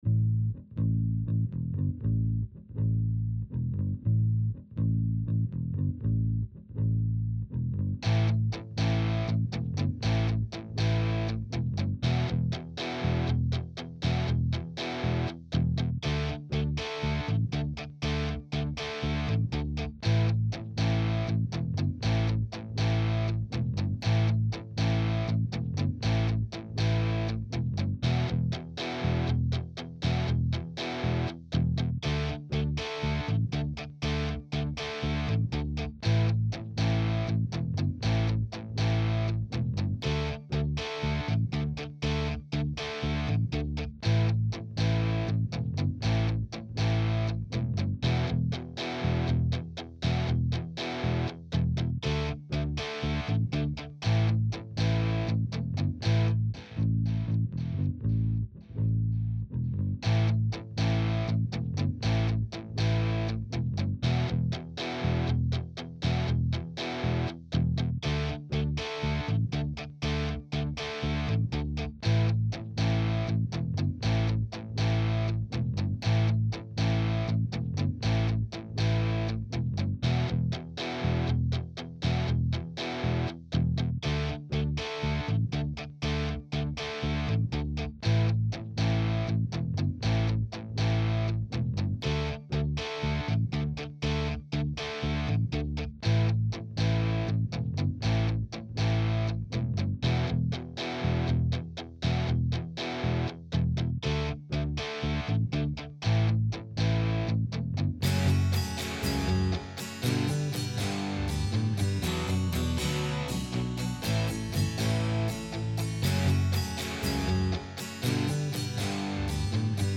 Recorded At MWEMUSIC